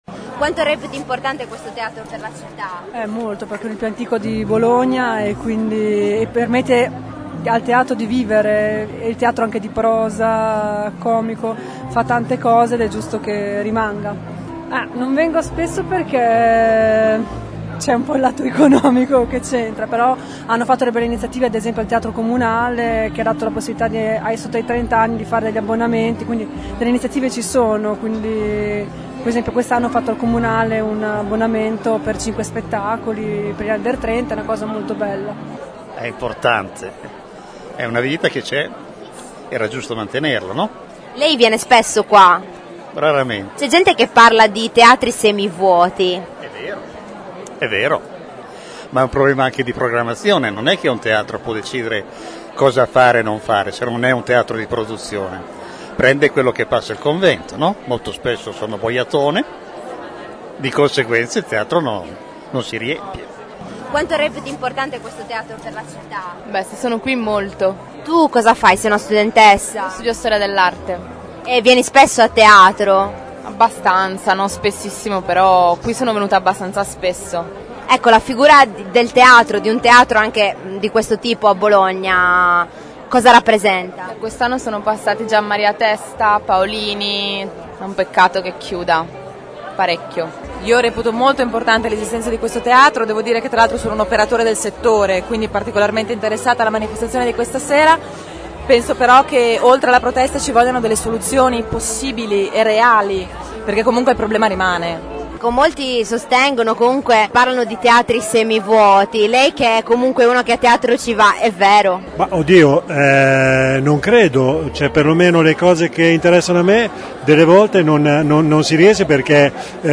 Le voci del pubblico